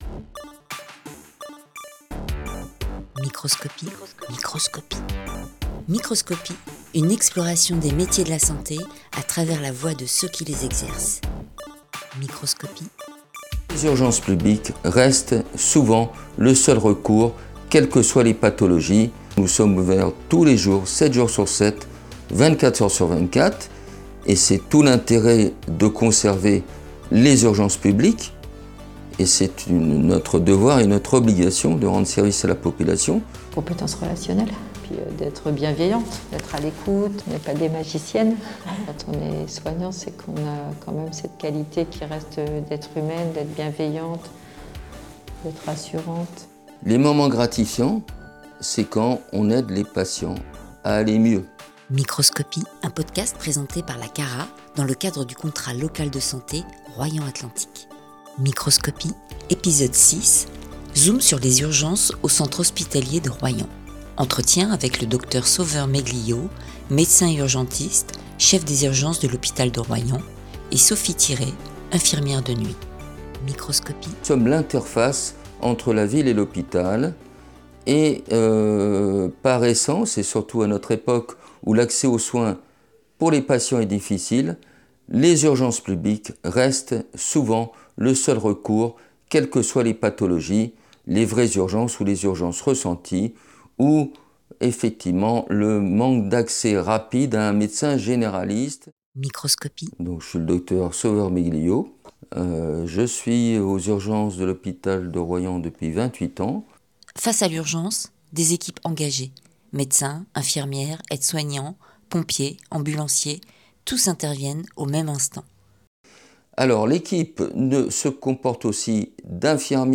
À travers des témoignages sincères de professionnels en poste ou en formation sur le territoire de Royan Atlantique, partez à la découverte d’un univers aussi passionnant que méconnu.